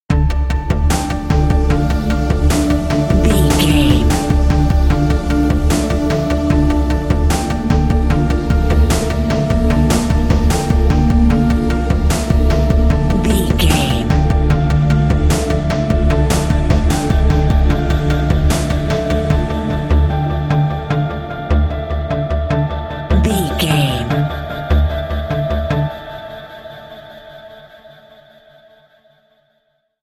Thriller
Aeolian/Minor
Slow
drum machine
synthesiser
electric piano
ominous
dark
suspense
haunting
creepy